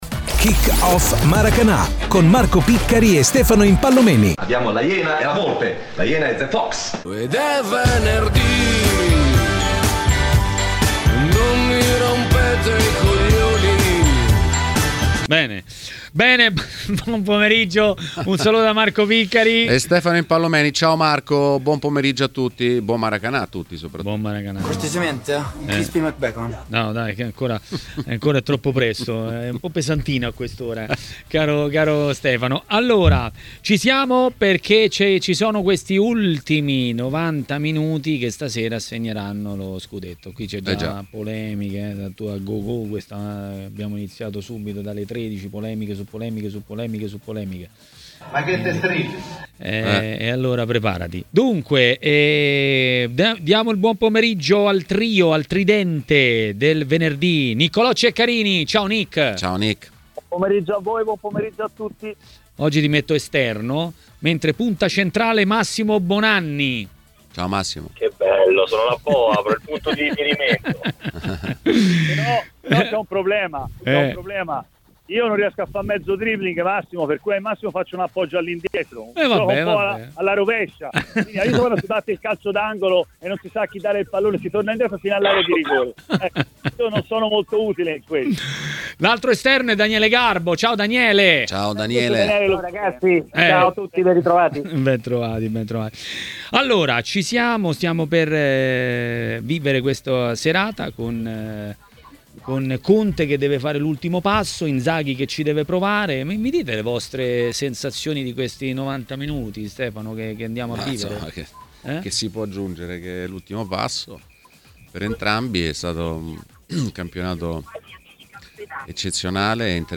a Maracanà, nel pomeriggio di TMW Radio, ha parlato dei temi della Serie A.
© registrazione di TMW Radio Facebook twitter Altre notizie